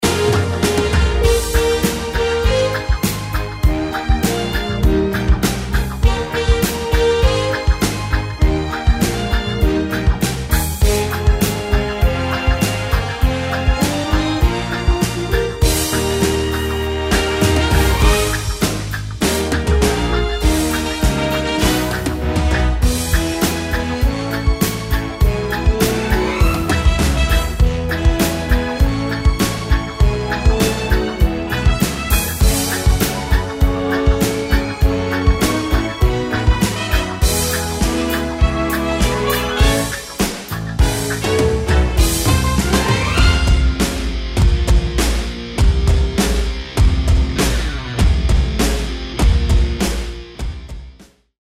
Instrumental
backing track